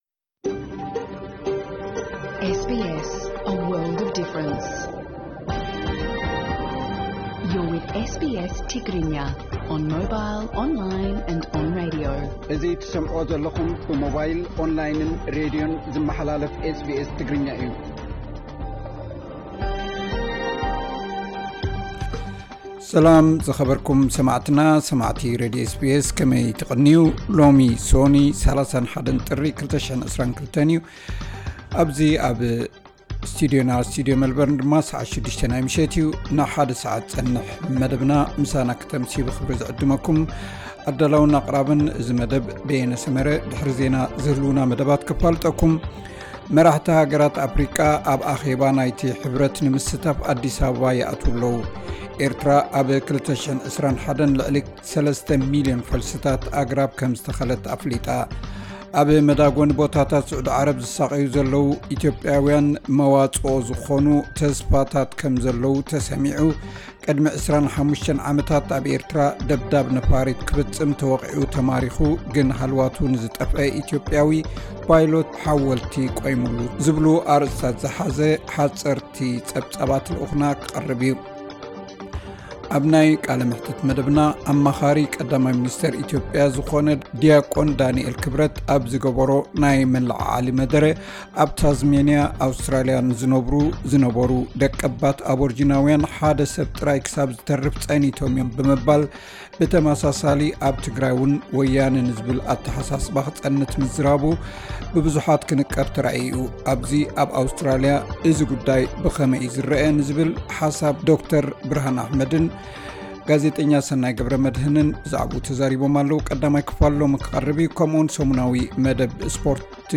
ዕለታዊ ዜና SBS ትግርኛ (31 ጥሪ 2022)